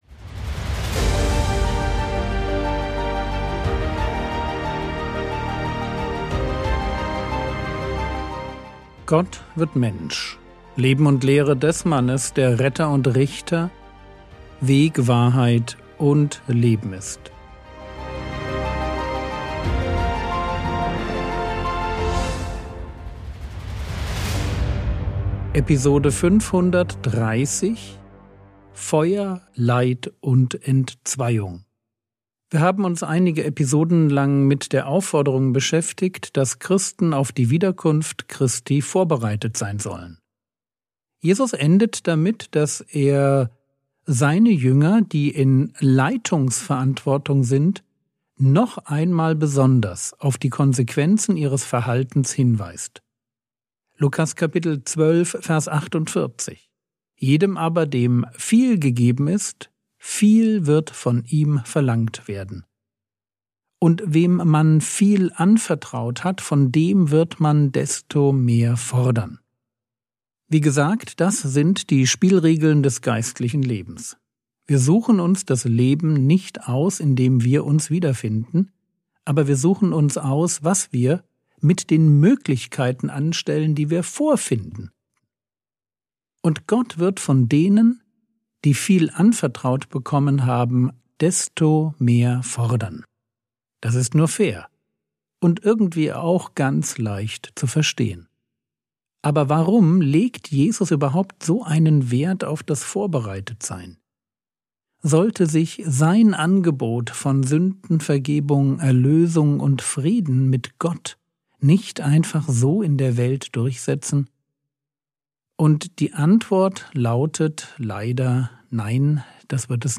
Frogwords Mini-Predigt